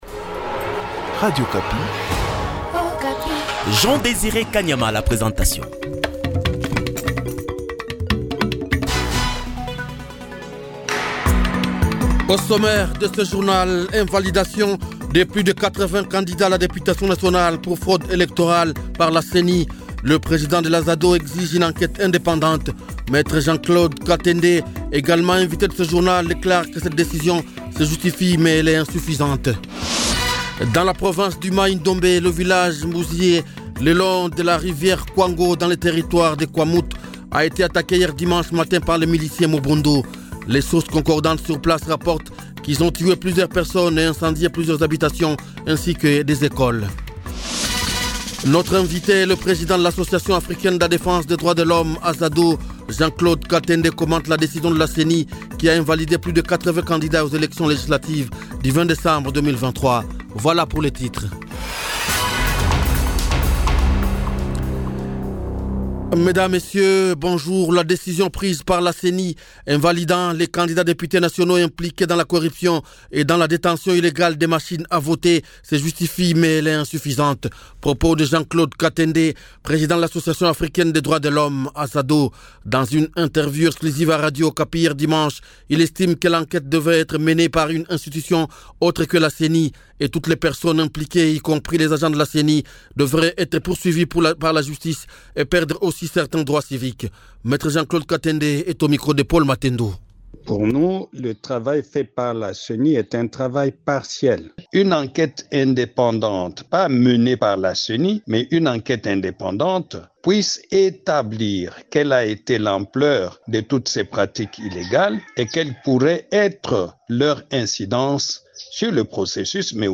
Journal Matin